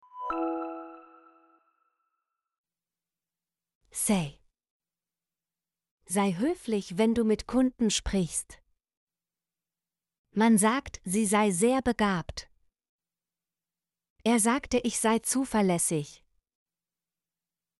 sei - Example Sentences & Pronunciation, German Frequency List